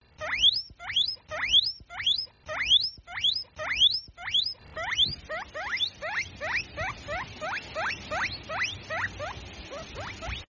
Tiếng Chuột lang kêu
Thể loại: Tiếng động vật hoang dã
Description: Tiếng chuột lang kêu là những âm thanh đặc trưng như “chiếp chiếp”, “ríu rít”, “cót két” hay “kêu lích chích” mà chuột lang phát ra khi đói, vui mừng, tò mò hoặc muốn giao tiếp.
tieng-chuot-lang-keu-www_tiengdong_com.mp3